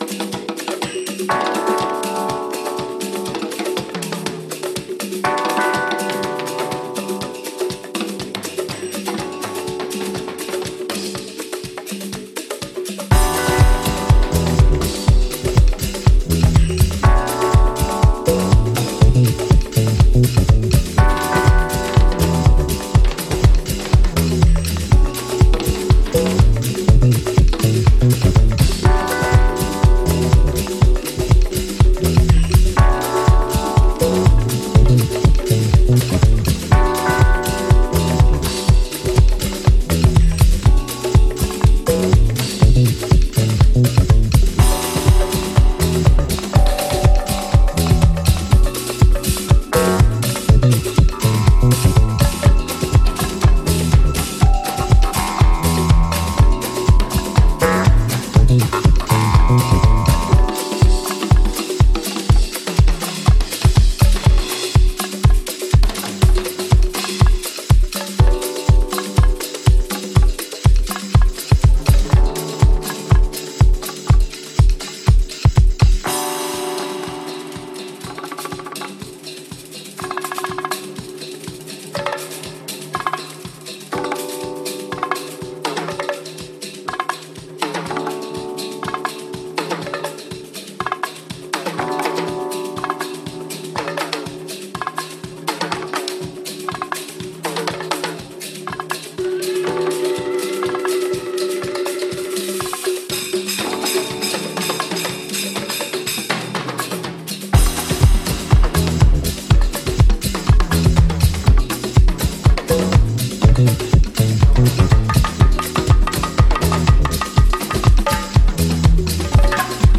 sampling audio fragments of old family videos.
full of percussions and rhythms
House